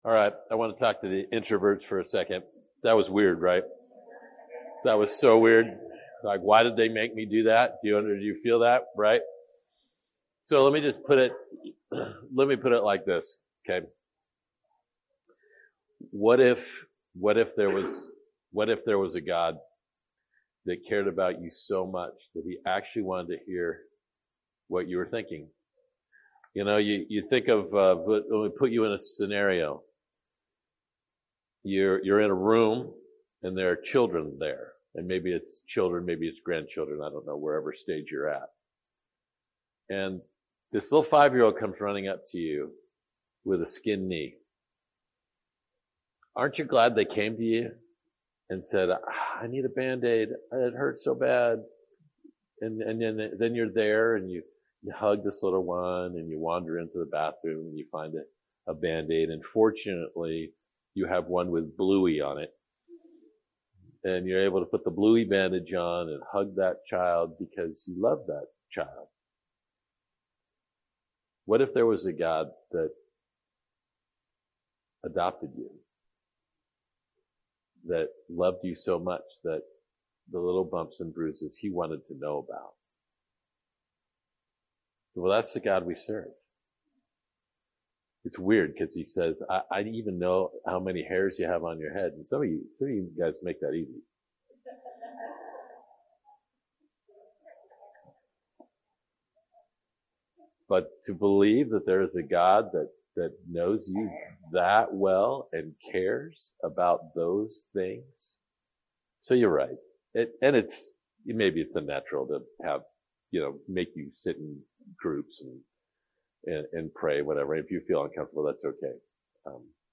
Sermons | Covenant Grove Church